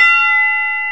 TUB BELL F4.wav